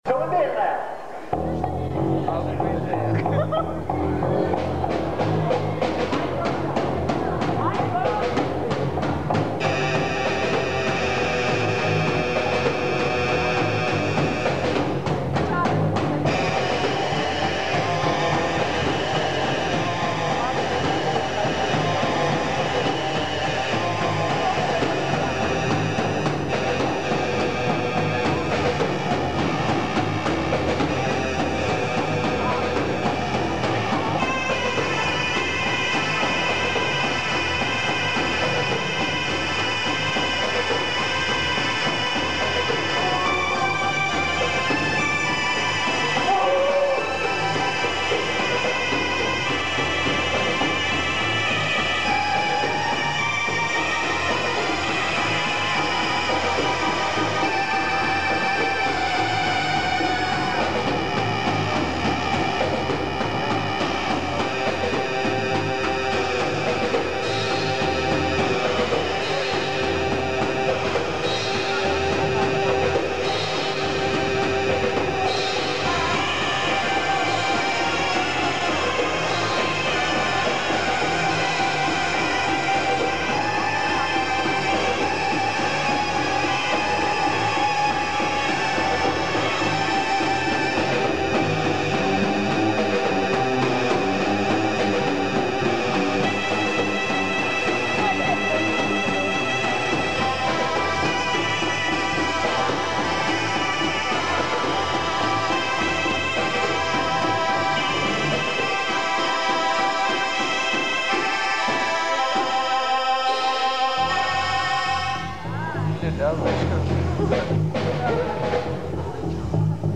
КОНЦЕРТ В КИНОТЕАТРЕ "ПИОНЕР"
синтезатор
барабанная установка